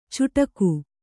♪ cuṭaku